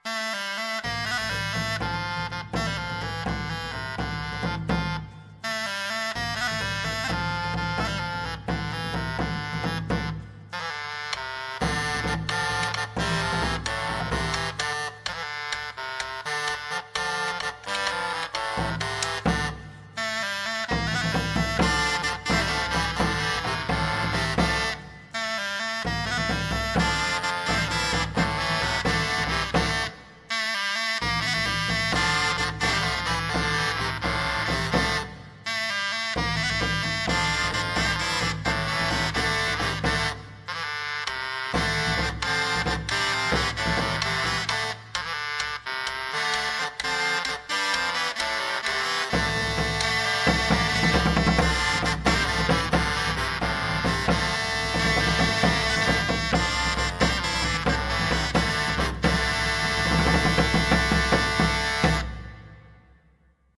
musiques médiévales
chant, flûte, vielle à roue, cromorne, chalemie, guimbarde
chant, flûte, harpe romane, cromorne, chalemie, guimbarde